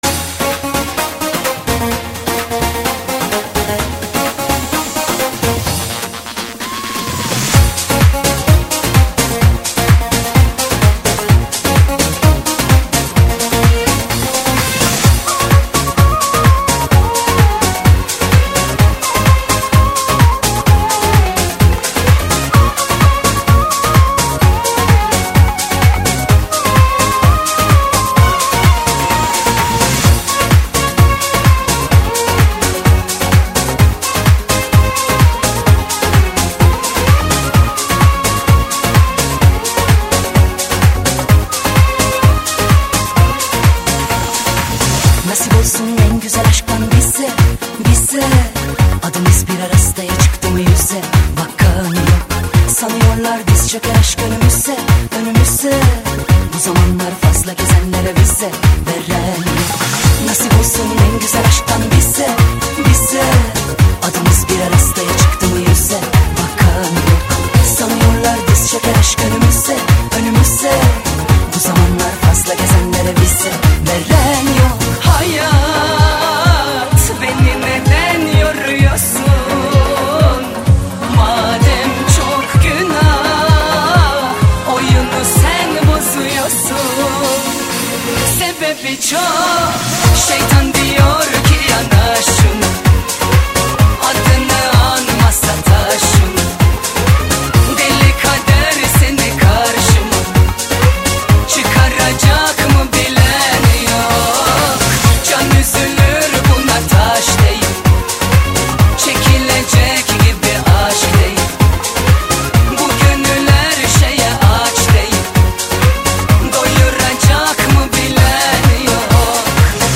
vostochnaya_muzyka.mp3